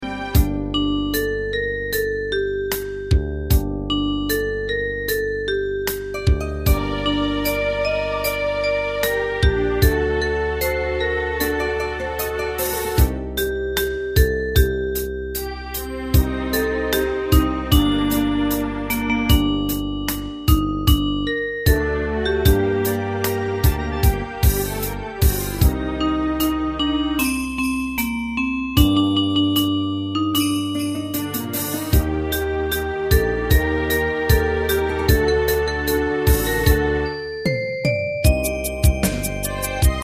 カテゴリー: ユニゾン（一斉奏） .
歌謡曲・演歌